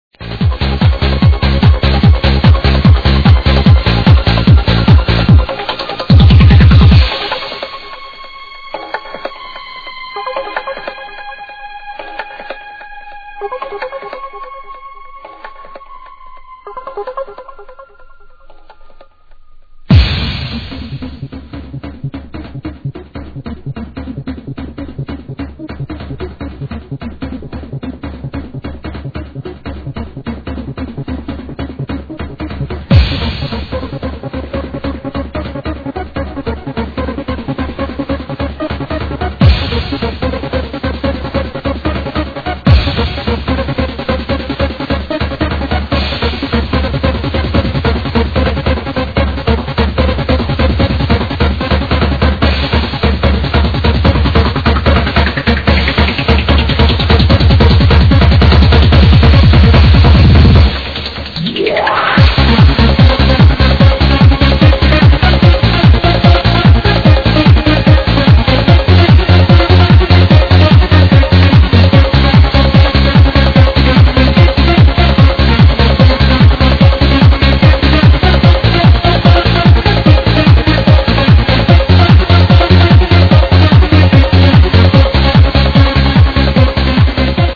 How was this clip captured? Yeah! I ripped it from a session of this year :-)